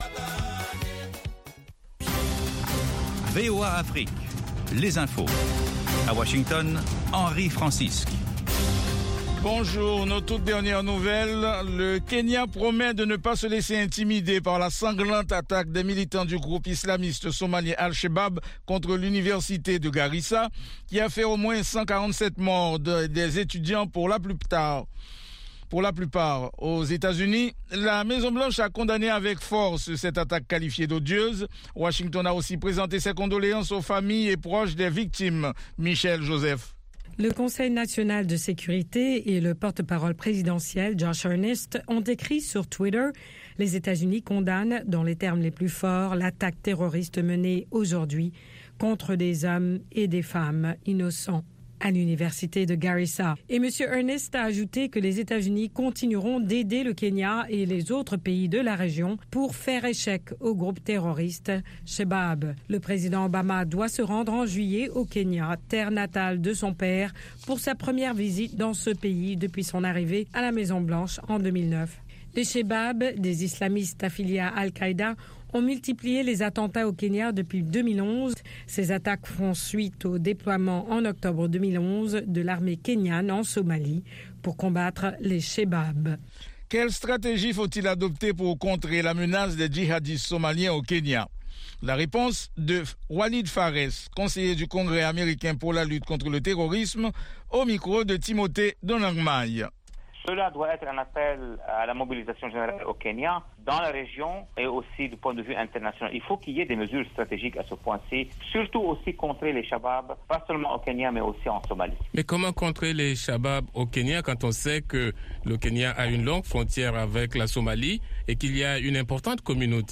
10 Minute Newscast